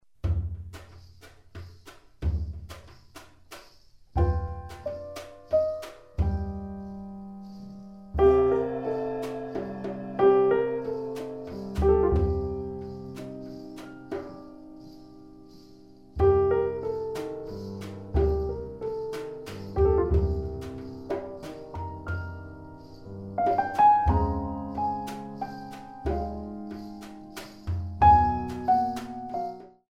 3/4  mm=92